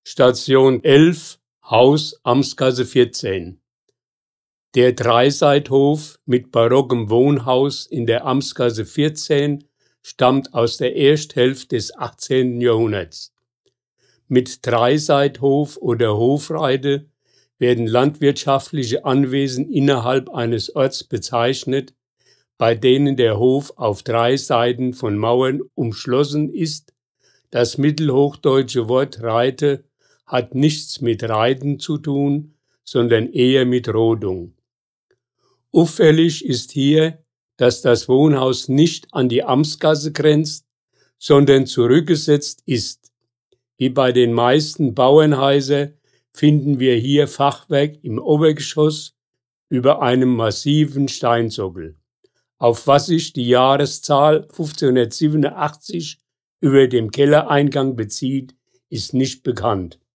Hier können Sie die Hörfassung der Stationsbeschreibung abspielen!